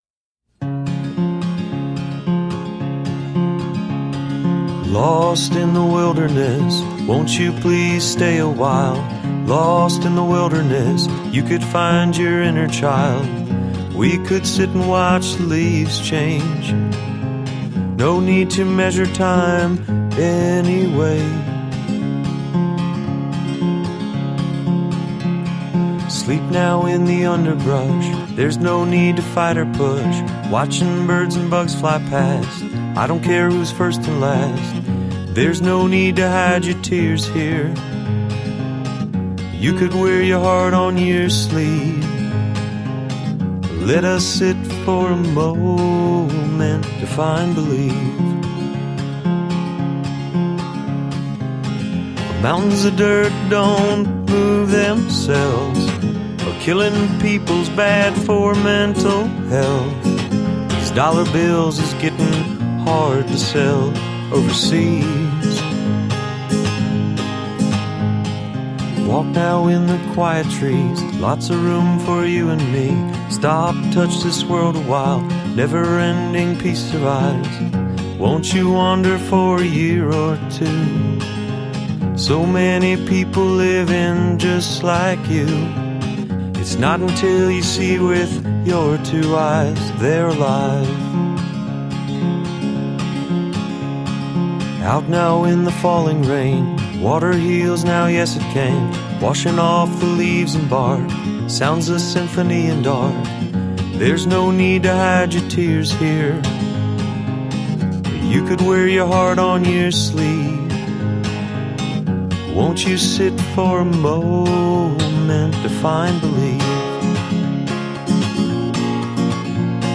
Harmonious thoughtful songs traverse popular forms.